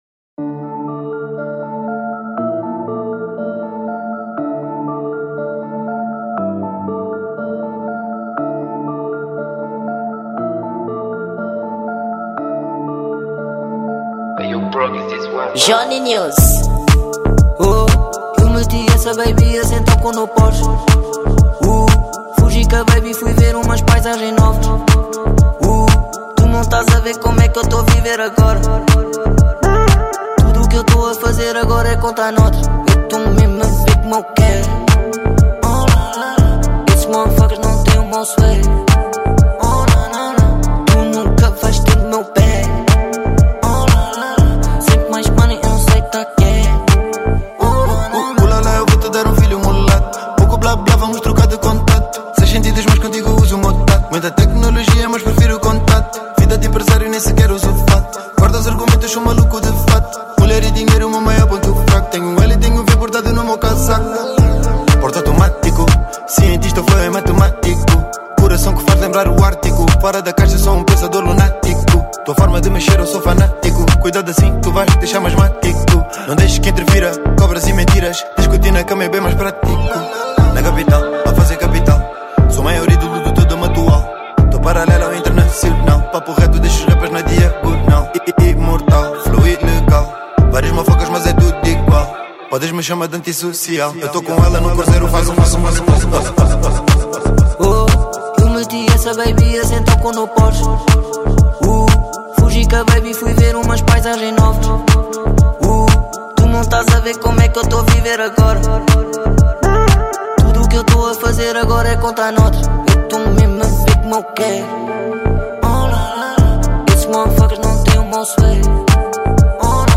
Gênero: Dance Hall